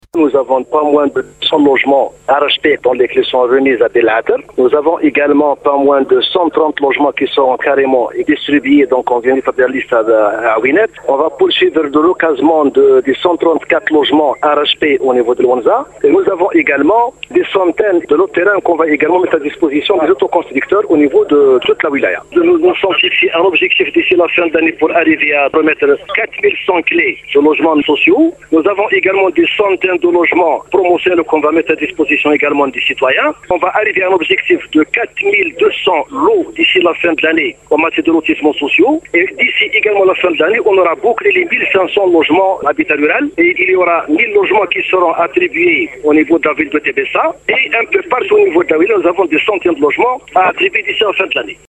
Des logements seront distribué mercredi soir à Tébéssa. Le wali Atellah Moulati,à la radio Chaîne 3 opération de relogement à Jijel.